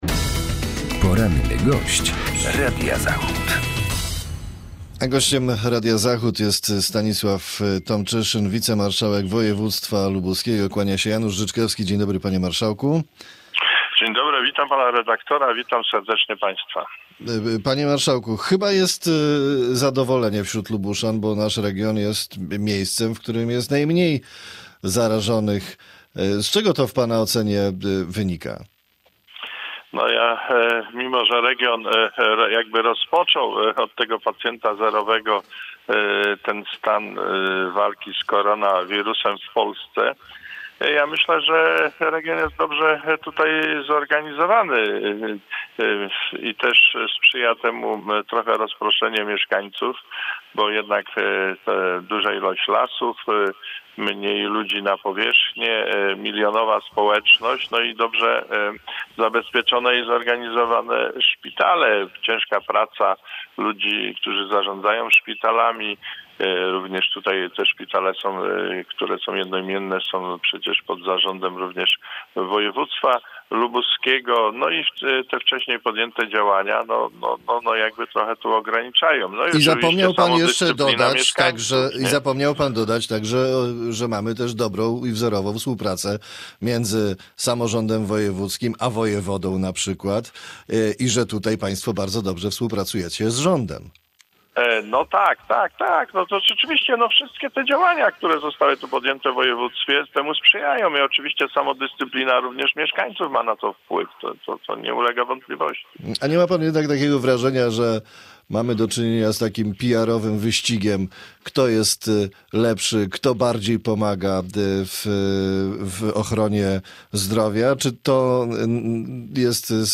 Z wicemarszałkiem województwa lubuskiego, członkiem PSL rozmawia